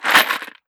Ammo Pickup 004.wav